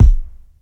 • 00s Verby Rap Kick Drum Sound B Key 401.wav
Royality free bass drum single hit tuned to the B note. Loudest frequency: 88Hz